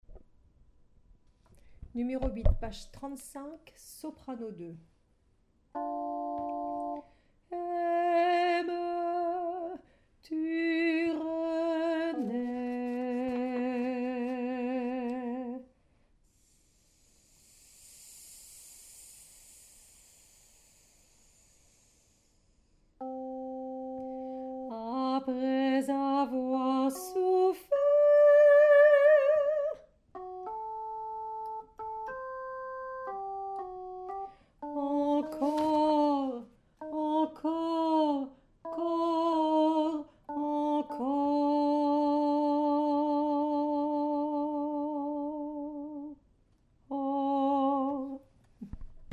Soprano 1